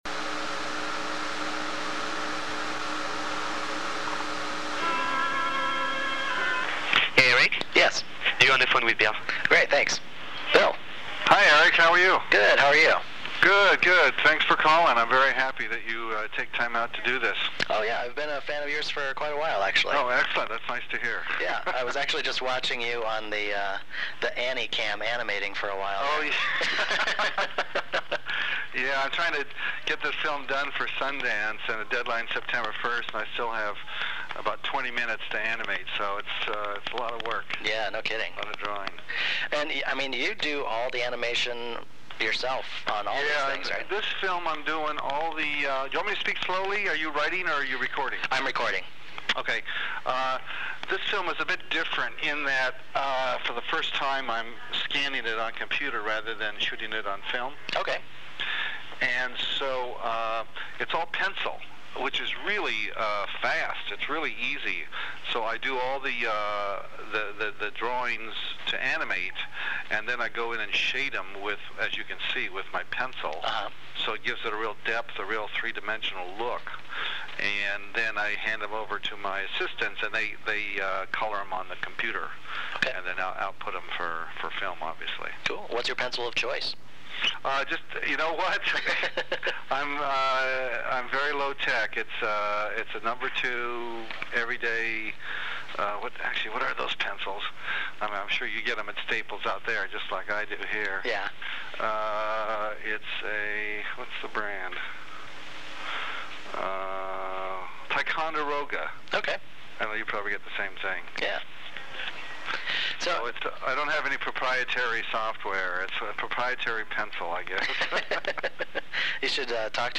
LAUNCH BILL PLYMPTON INTERVIEW (MP3 FILE)(NOTE: File is large and may take awhile to fully load.)